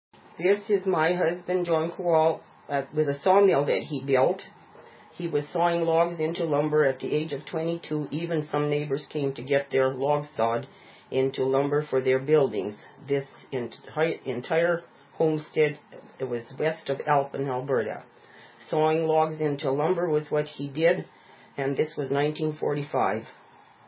Sawmill - Sound Clip